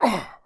dead_1_1.wav